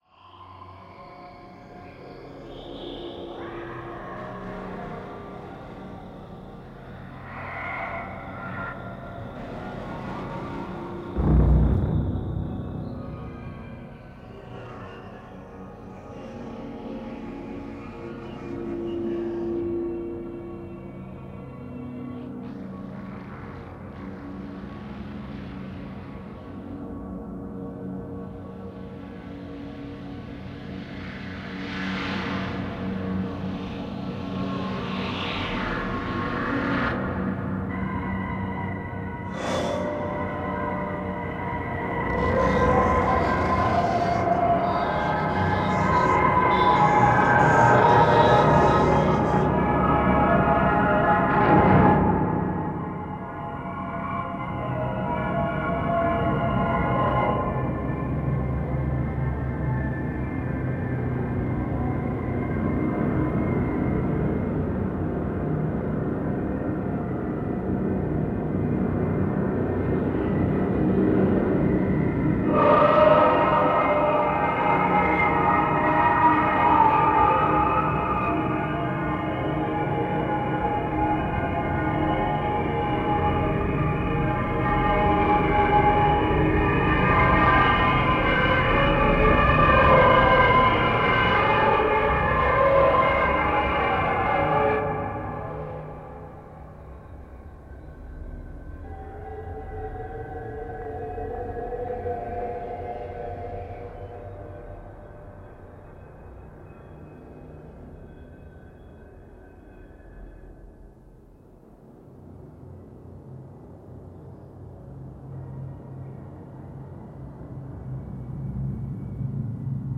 longform electroacoustic composition